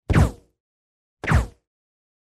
Звуки пиу
Приглушенный звук пистолета с глушителем